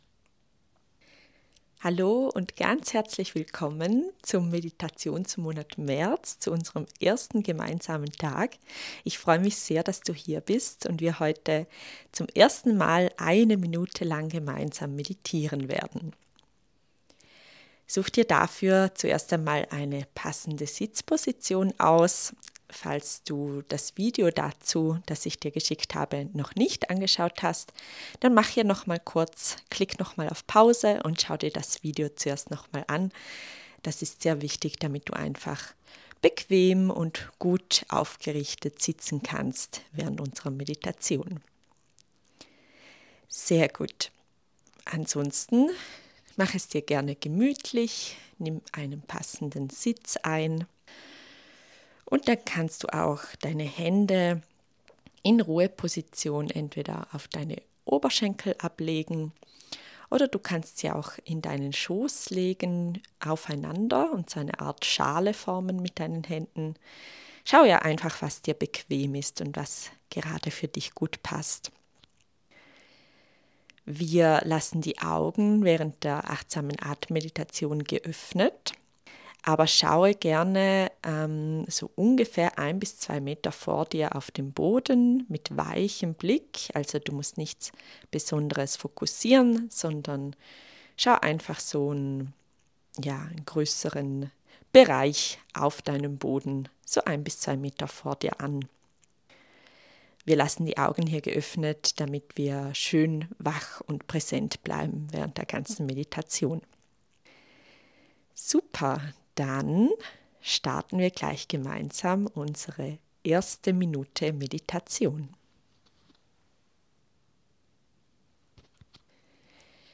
Deine Meditation